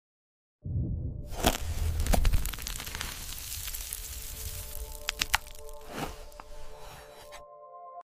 ASMR stormy cloud.